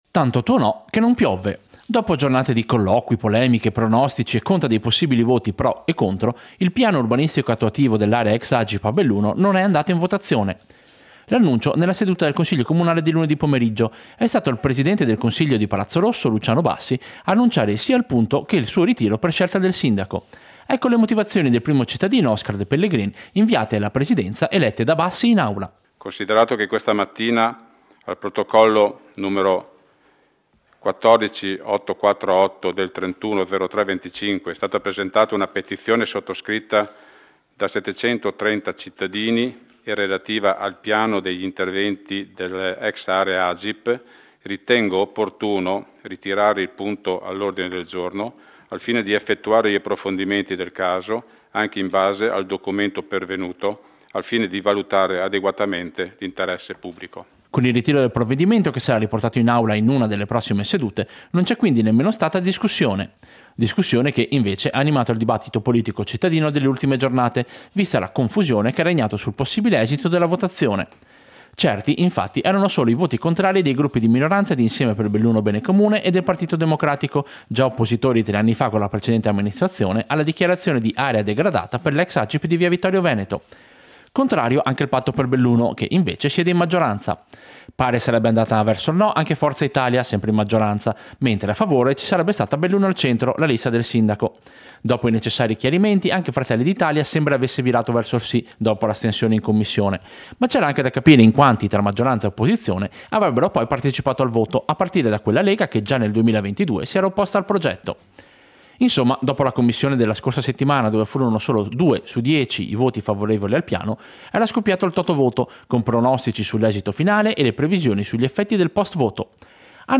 Servizio-Consiglio-comunale-ritiro-votazione-Agip.mp3